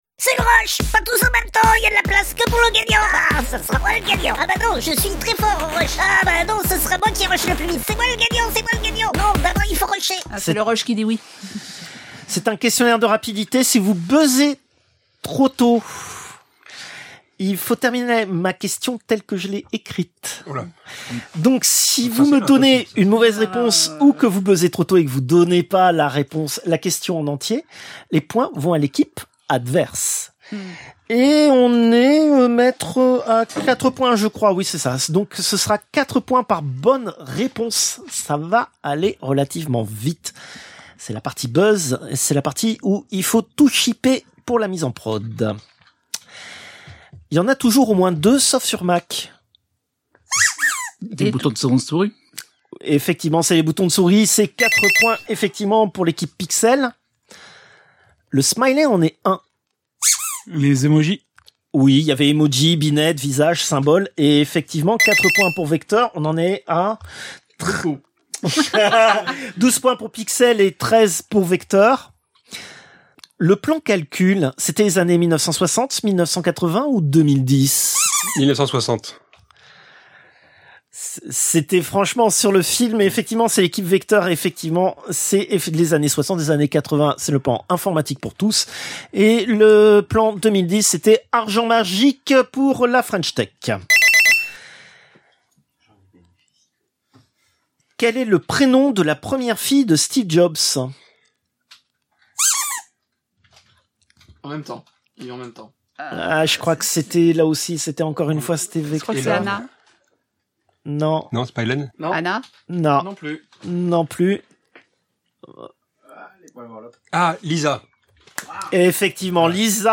Extrait de l'émission CPU release Ex0227 : lost + found (un quart null).